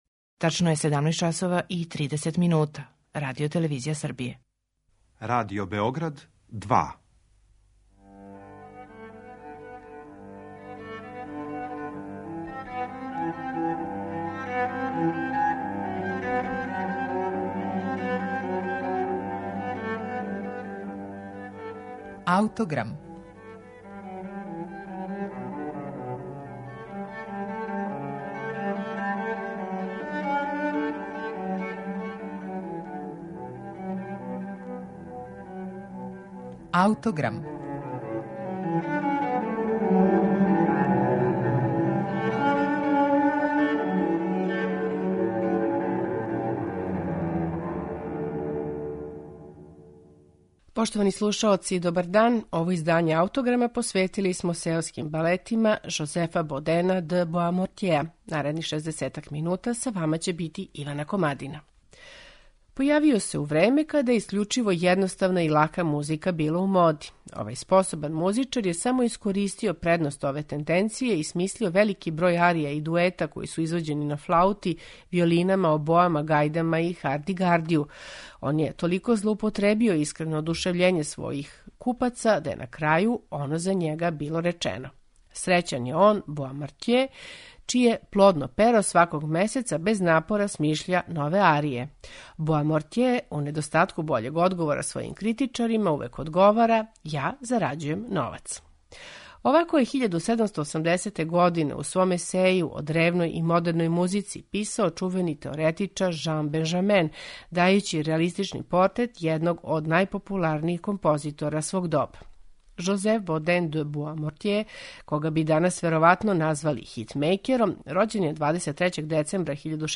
Посебно место у његовом опусу имају „Сеоски балети", најважније композиције које је компоновао за тада популарне инструменте мизету и харди-гарди. У данашњем Аутограму представићемо четири Боамортјеова „Сеоска балета", у интерпретацији анасамбла Le Concert Spirituel, под управом Ерва Никеа.